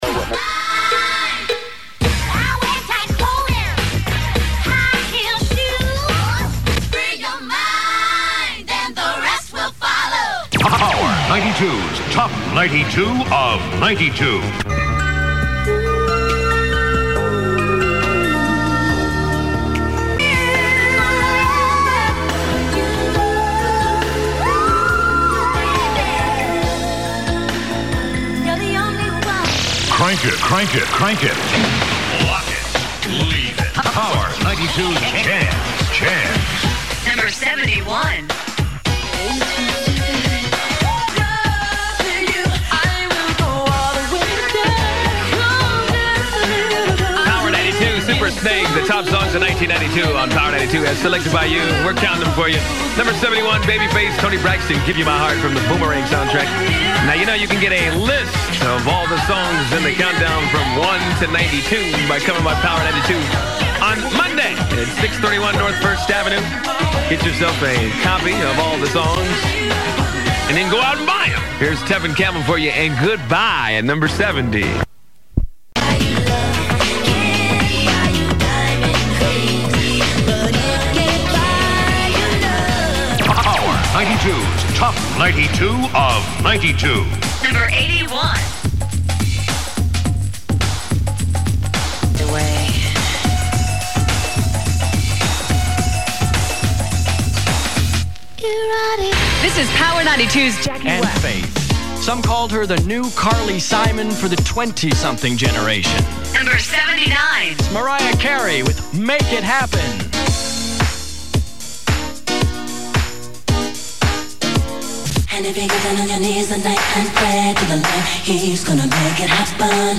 I loved the high-energy, laser-sound-effect-dominated presentation style used by KKFR (and its clones) during this period.